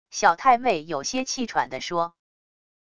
小太妹有些气喘地说wav音频